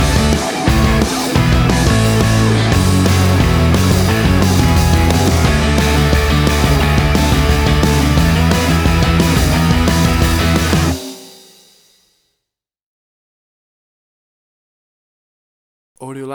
WAV Sample Rate: 16-Bit stereo, 44.1 kHz
Tempo (BPM): 176